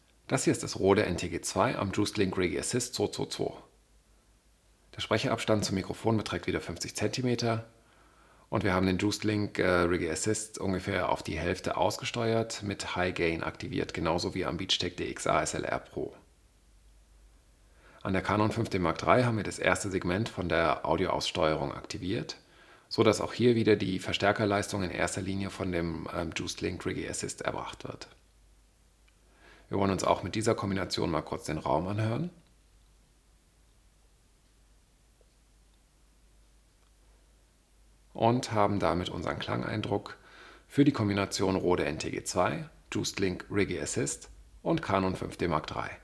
Der Abstand zwischen Richt-Mikrofon und Sprecher beträgt stets 0,5m. Das Mikro wurde auf einem Tonstativ oberhalb des Sprechers montiert.
Rode NTG-2 via juicedLink Riggy Assist 222 an Canon 5D Mark III